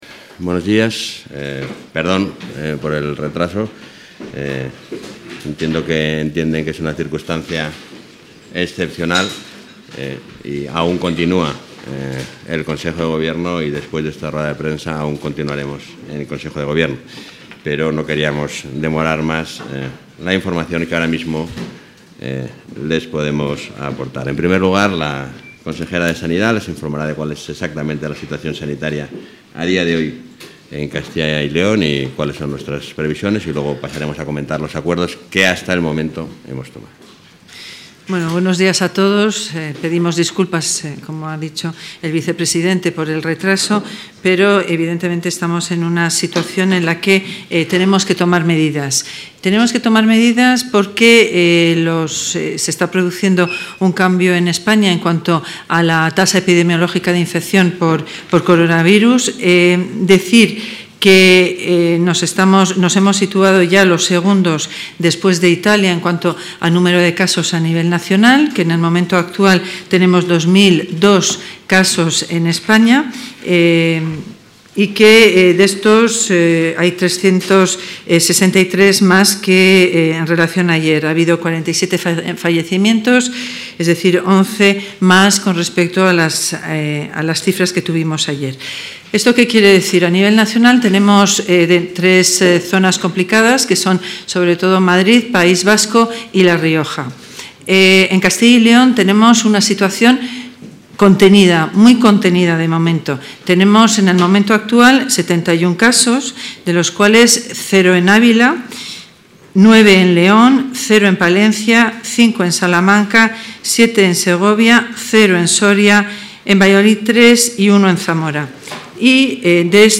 Rueda de prensa.